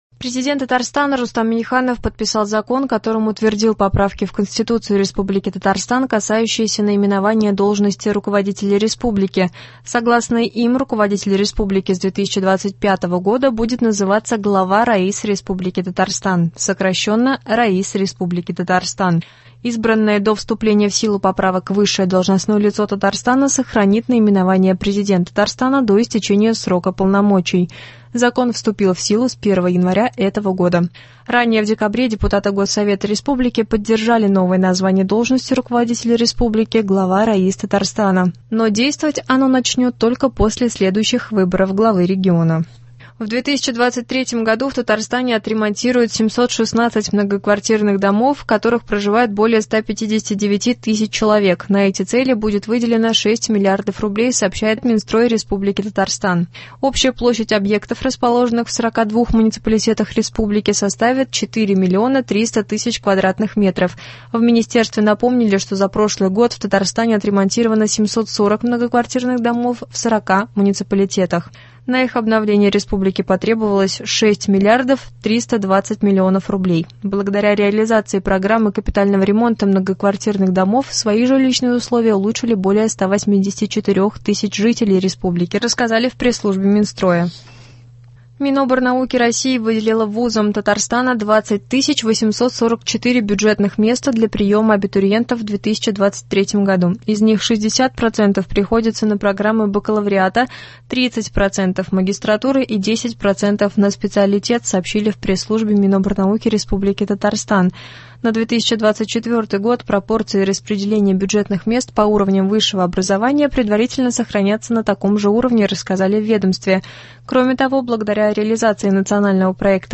Новости (09.01.23)